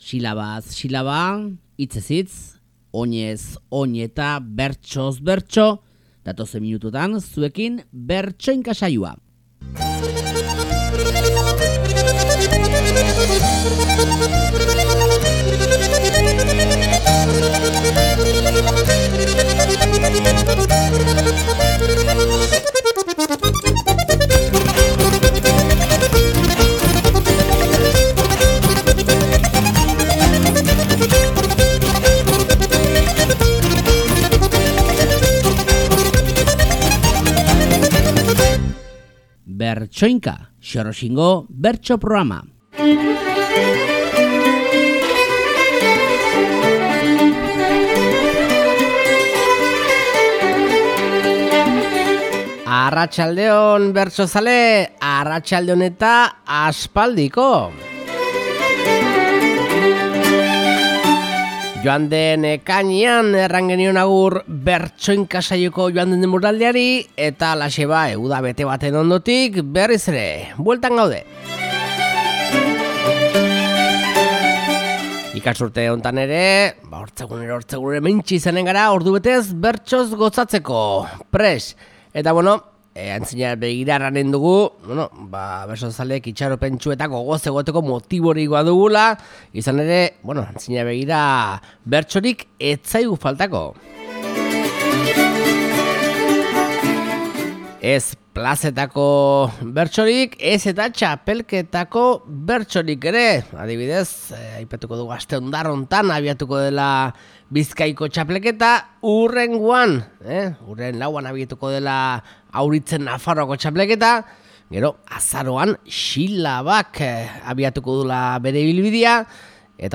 Lakuntzako bestetako jaialdiko bertsoekin abiatuko dugu Bertsoinka saiokoaurtengo sasoia.